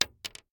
Bullet Shell Sounds
pistol_wood_3.ogg